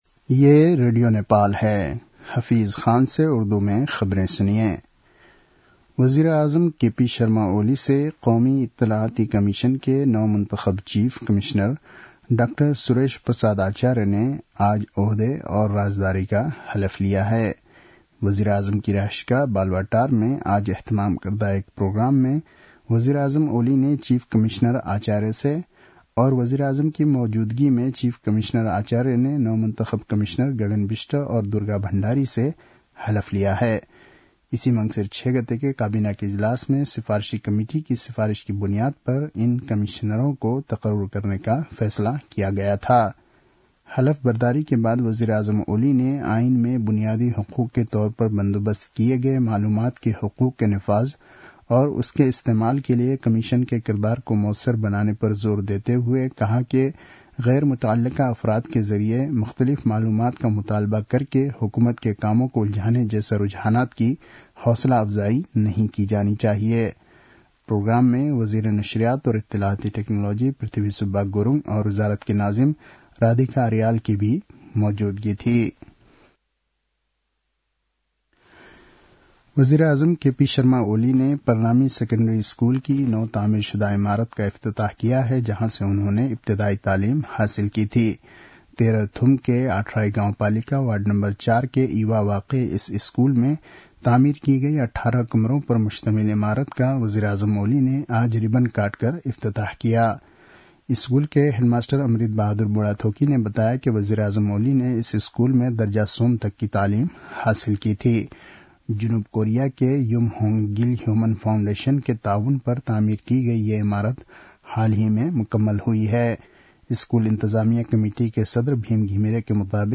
उर्दु भाषामा समाचार : २७ मंसिर , २०८१